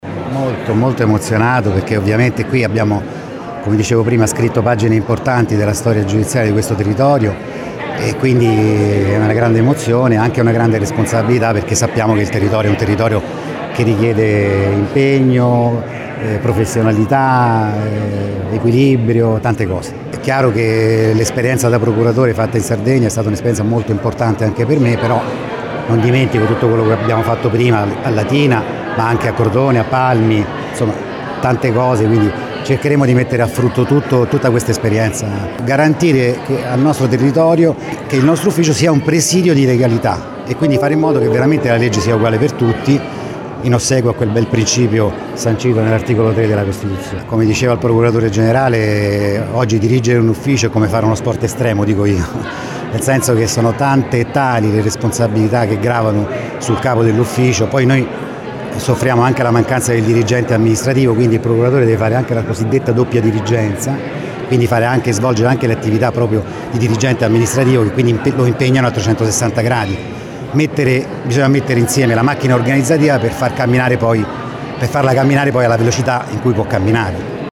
LATINA – Nell’aula di corte d’assise del Tribunale di Latina si è svolta oggi l’affollata cerimonia di insediamento del Procuratore Capo di Latina, Gregorio Capasso, presente il procuratore generale di Roma, Giuseppe Amato.